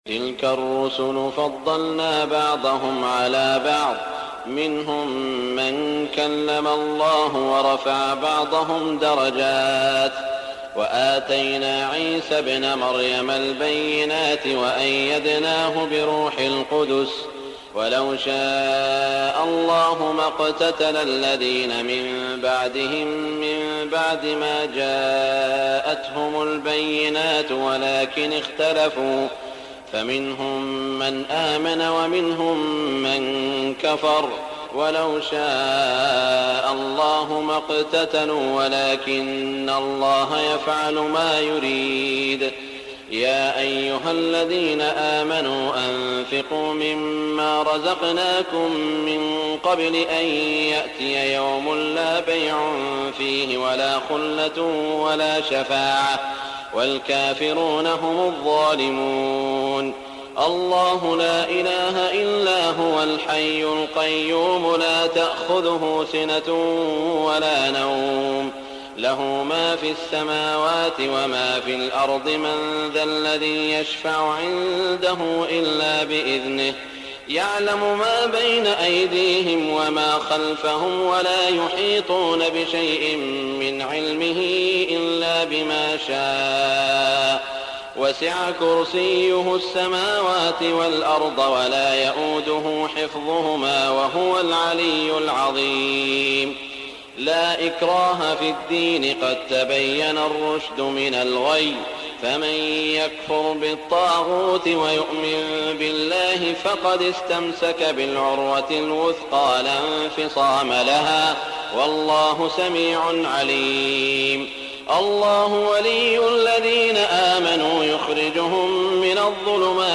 تهجد ليلة 23 رمضان 1418هـ من سورتي البقرة (254-286) و آل عمران (1-32) Tahajjud 23 st night Ramadan 1418H from Surah Al-Baqara and Aal-i-Imraan > تراويح الحرم المكي عام 1418 🕋 > التراويح - تلاوات الحرمين